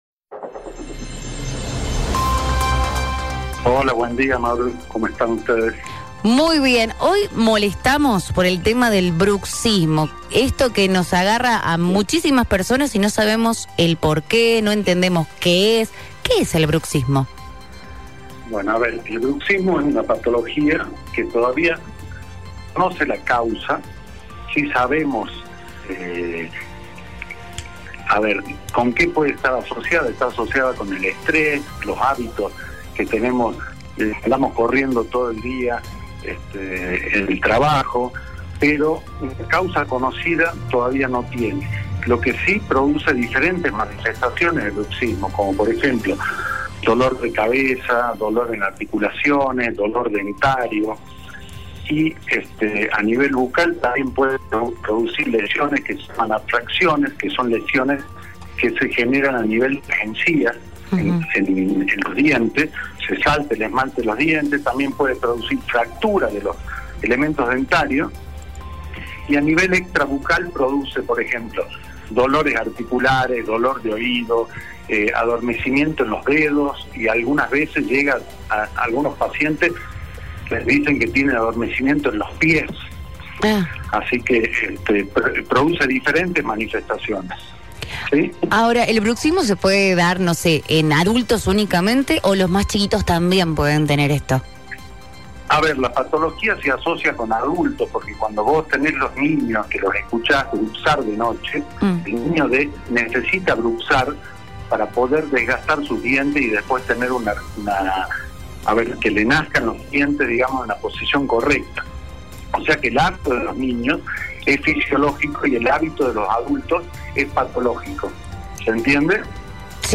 En el podcast de hoy, dialogamos con el Odontólogo